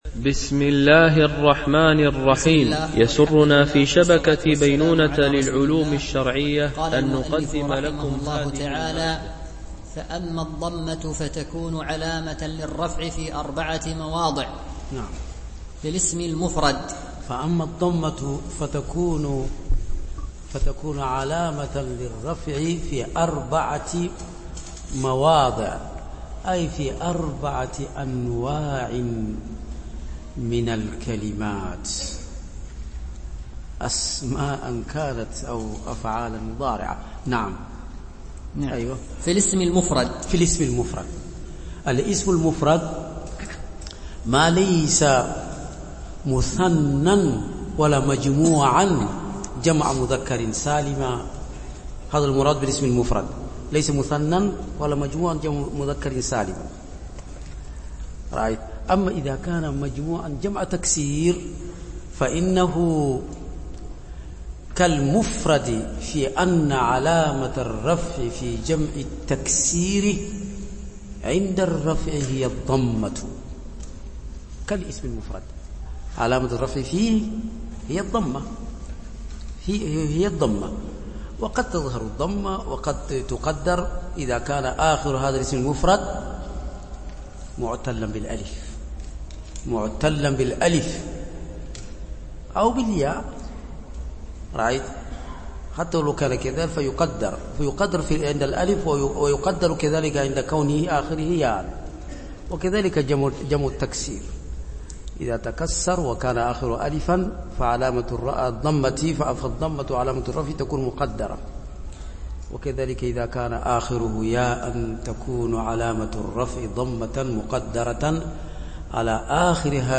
شرح المقدمة الآجرومية ـ الدرس 3